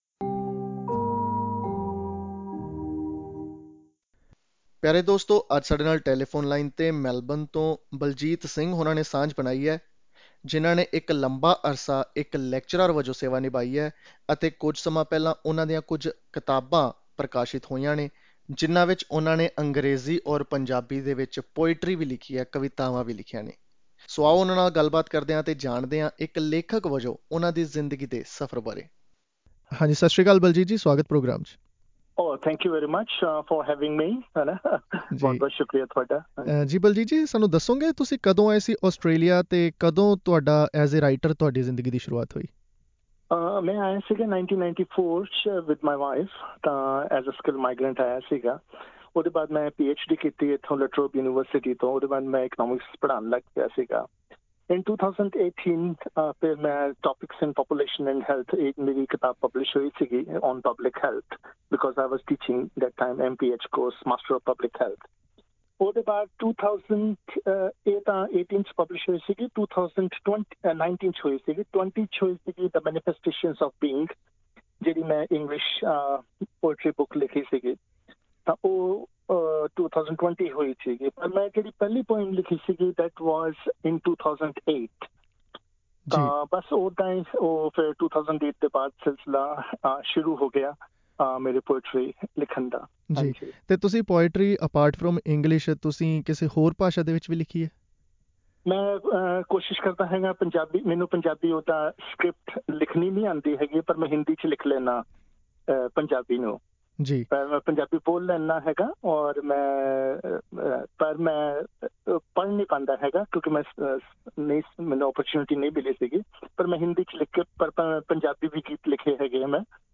ਇੱਕ ਖਾਸ ਗੱਲਬਾਤ